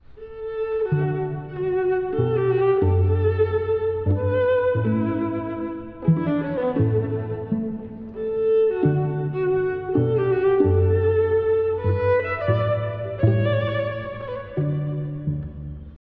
第2楽章 歩くような拍で奏でる叙情
どこか安らぎを感じさせる章。
歩くような三声の拍の上で、1stVnが独白するように旋律を奏でます。
結構休符が多い楽章。